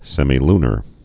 (sĕmē-lnər, sĕmī-)